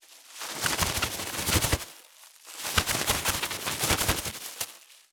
659コンビニ袋,ゴミ袋,スーパーの袋,袋,買い出しの音,ゴミ出しの音,袋を運ぶ音,
効果音